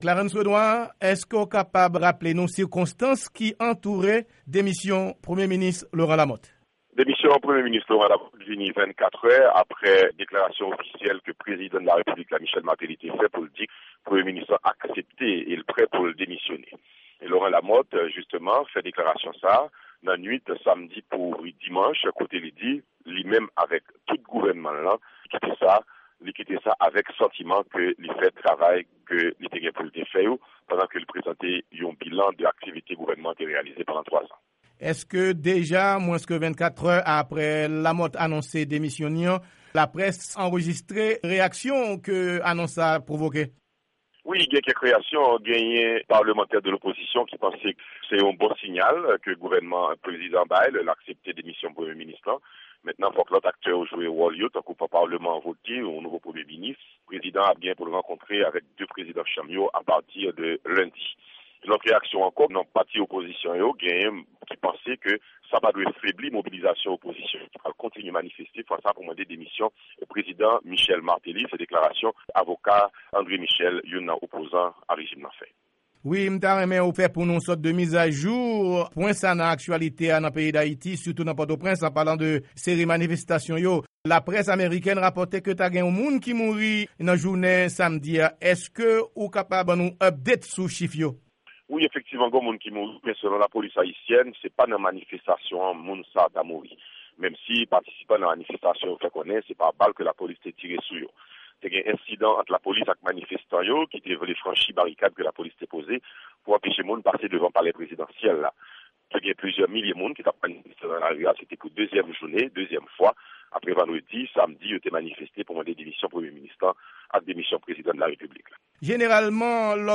Intèvyou